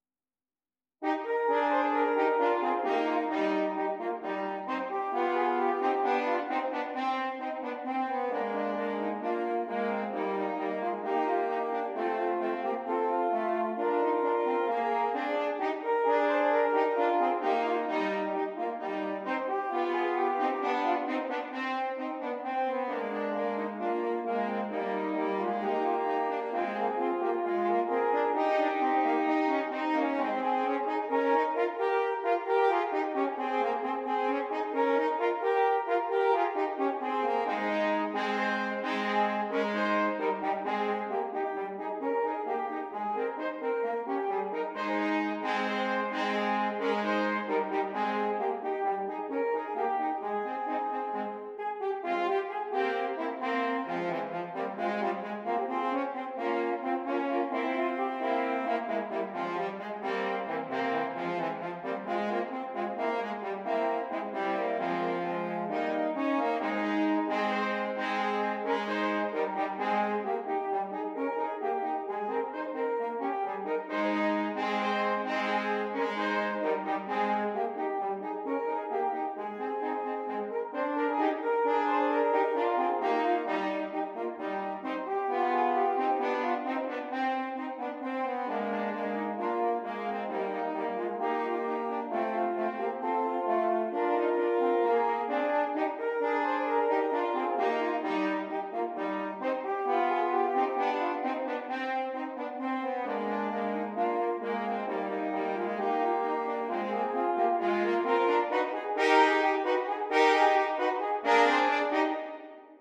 3 F Horns